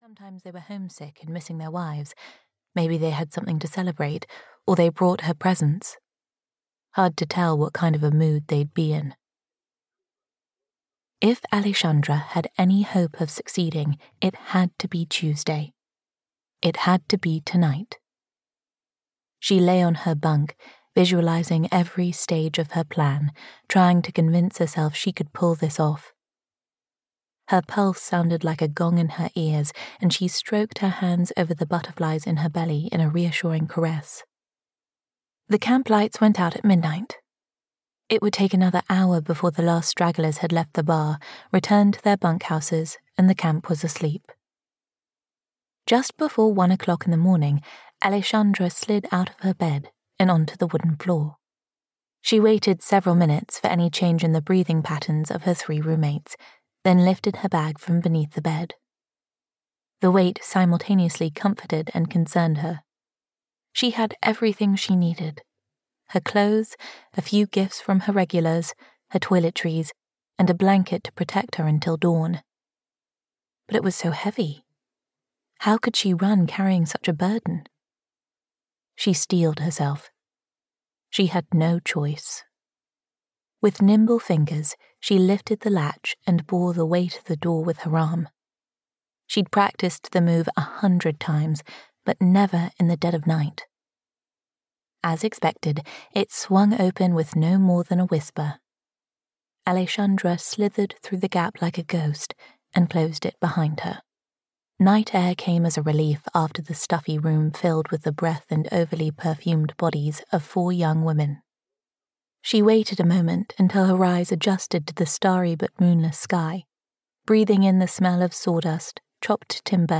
Black River (EN) audiokniha
Ukázka z knihy